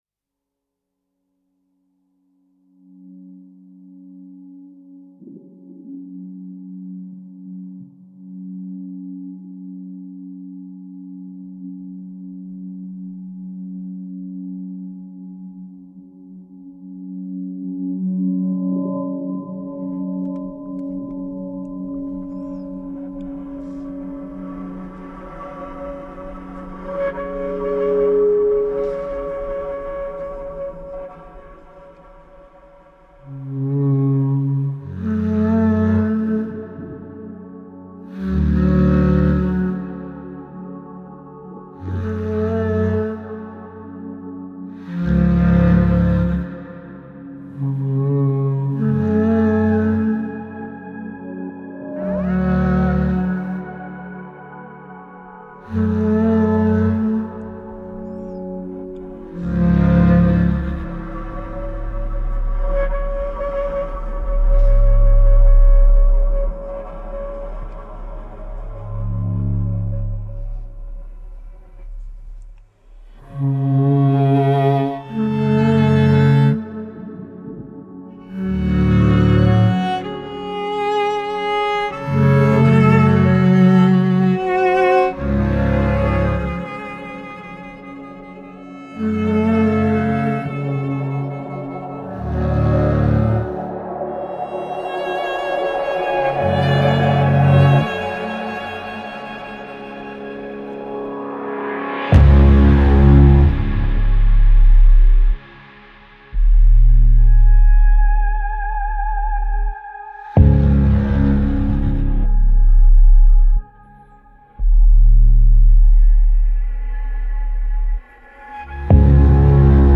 Dark Cinematic Drama